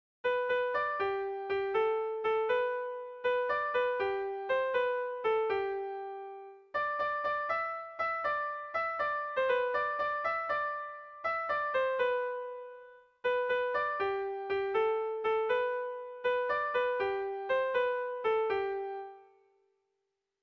Irrizkoa
Seikoa, handiaren moldekoa, 4 puntuz (hg) / Lau puntukoa, handiaren moldekoa (ip)
ABDAB